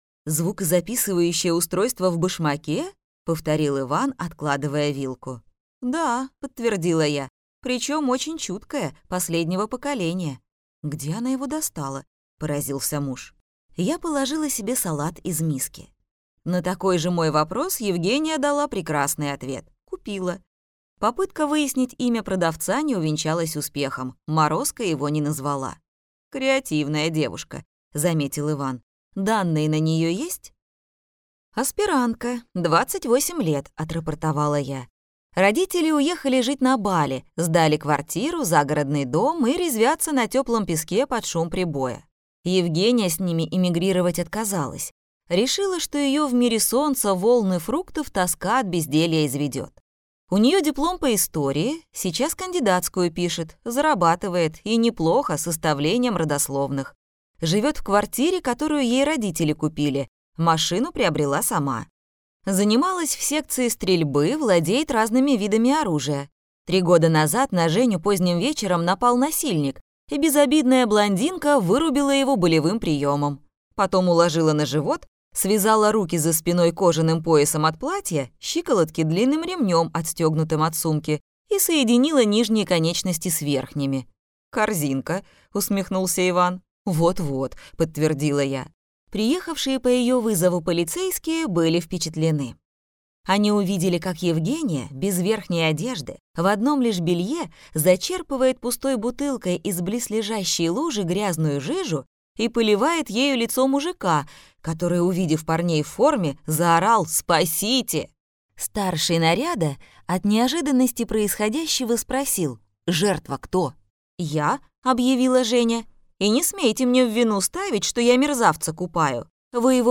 Аудиокнига Львиная доля серой мышки - купить, скачать и слушать онлайн | КнигоПоиск